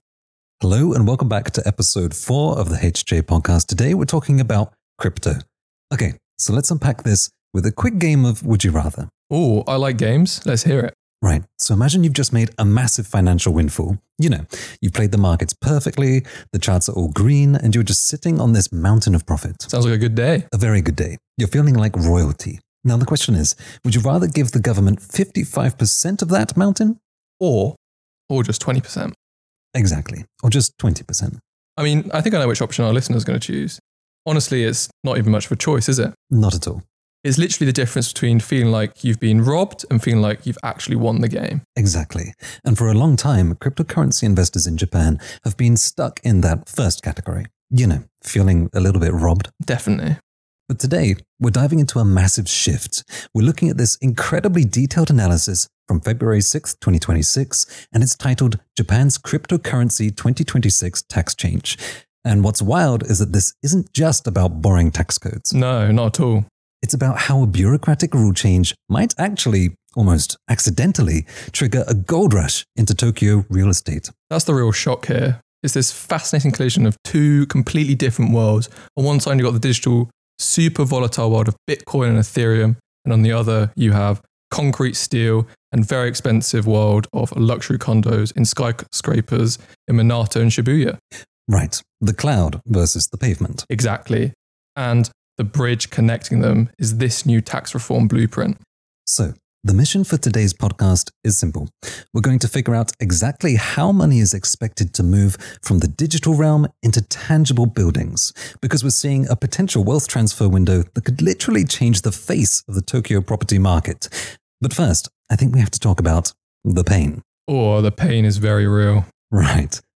The hosts walk through the legislative timeline, from the December 2025 reform outline to amendment bills entering the diet in early 2026, with a likely effective date of January 1, 2028, and explain why this two-year gap creates a powerful incentive for investors to hold rather than sell.